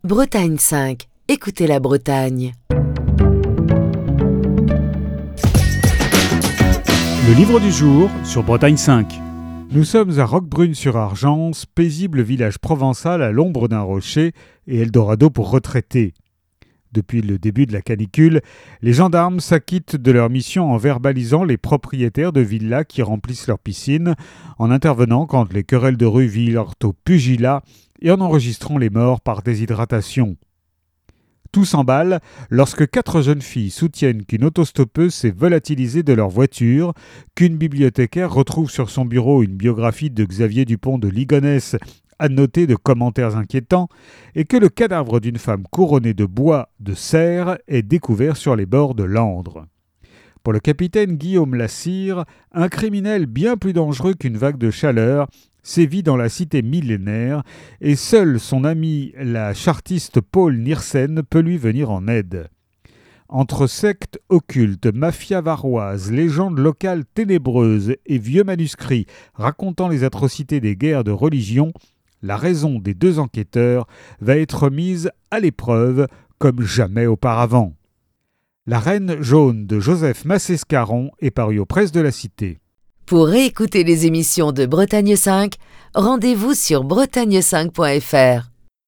Chronique du 29 avril 2024.